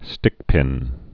(stĭkpĭn)